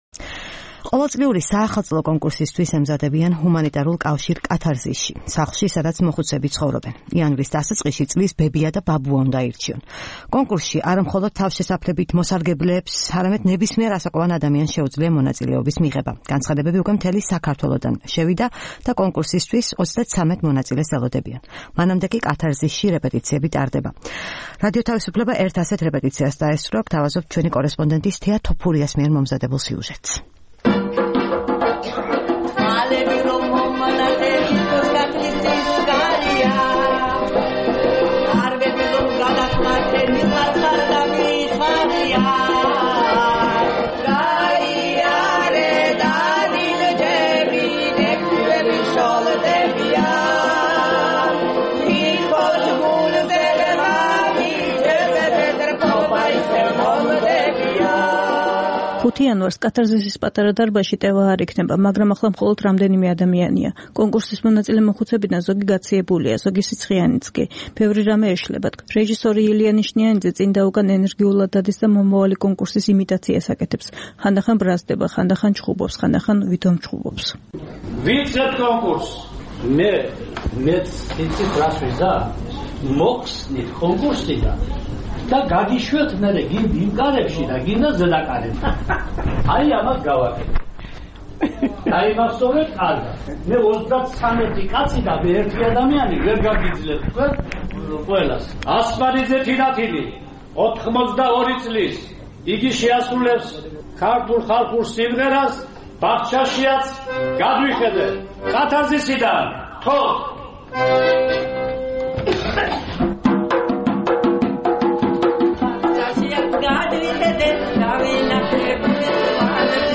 რეპეტიცია წლის ბებიისა და პაპის ასარჩევად
ჰუმანიტარულ კავშირ „კათარზისში“, სადაც მოხუცები ცხოვრობენ, ყოველწლიური საახალწლო კონკურსისთვის ემზადებიან. იანვრის დასაწყისში წლის ბებია და პაპა უნდა აირჩიონ. კონკურსში არა მხოლოდ თავშესაფრების ბენეფიციარებს, არამედ ნებისმიერ ასაკოვან ადამიანს შეუძლია მონაწილეობა. განცხადებები უკვე მთელი საქართველოდან შემოვიდა და კონკურსისთვის 33 მონაწილეს ელოდებიან. მანამდე კი „კათარზისში“ რეპეტიციები ტარდება. რადიო თავისუფლება ერთ ასეთ რეპეტიციას დაესწრო.